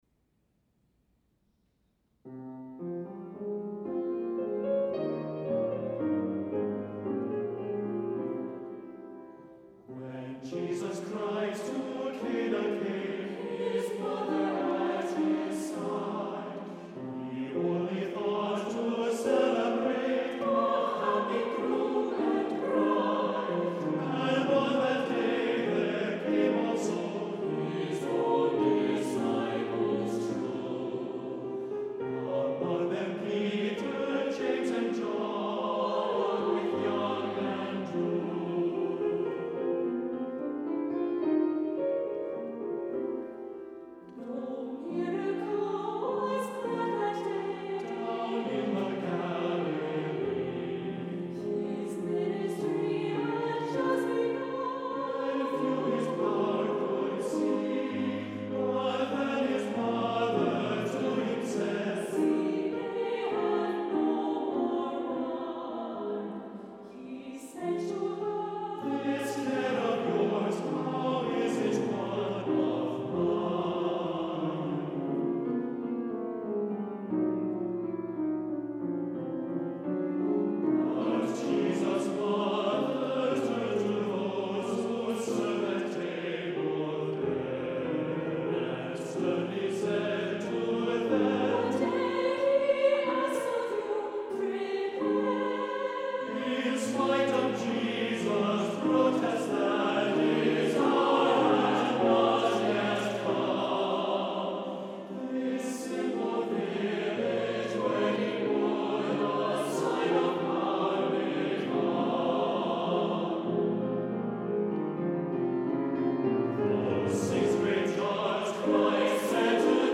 lovely arrangement for SAB and keyboard
• mostly middle voice writing with imitative touches